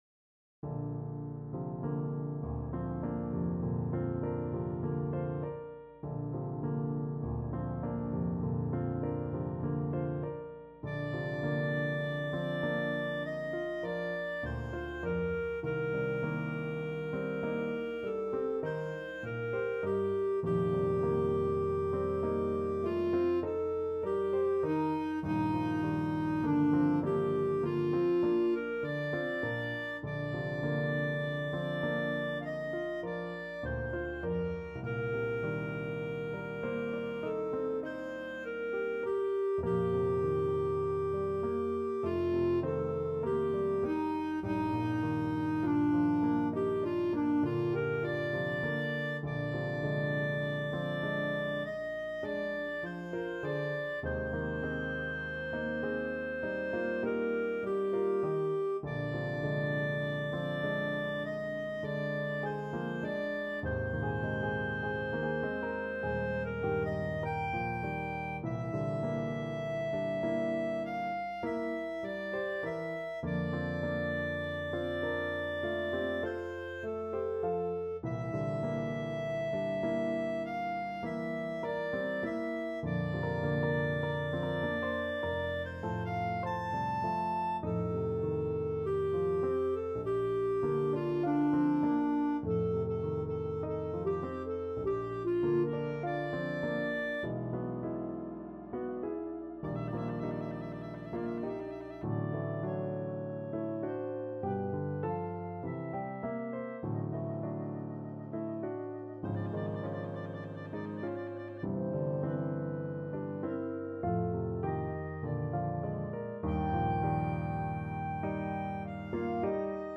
Free Sheet music for Clarinet
Bb major (Sounding Pitch) C major (Clarinet in Bb) (View more Bb major Music for Clarinet )
Lento =50
4/4 (View more 4/4 Music)
Clarinet  (View more Intermediate Clarinet Music)
Classical (View more Classical Clarinet Music)